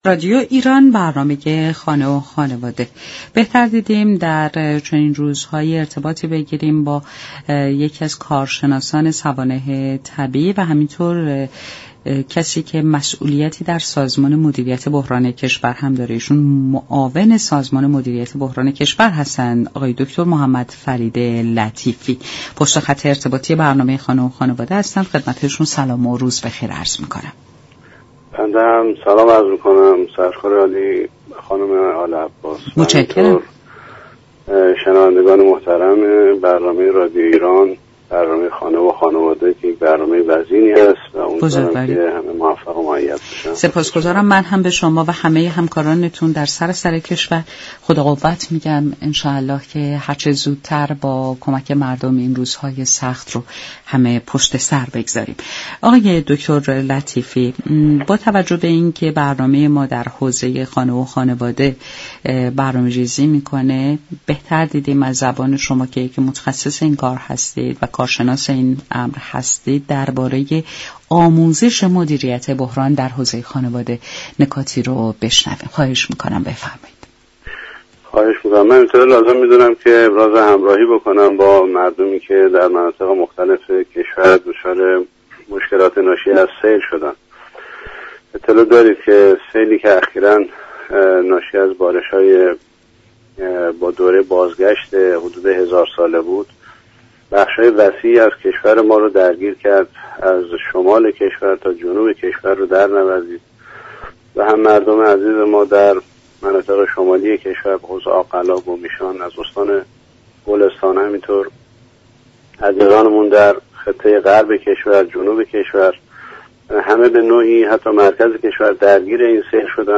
معاون سازمان مدیریت بحران كشور در گفت و گو با رادیو ایران گفت: كشورمان در بخش آموزش مردم و اقدامات دستگاه های اجرایی همچنان با ضعف ها و كاستی هایی مواجه است.
این گفت و گو را در ادامه باهم می شنویم.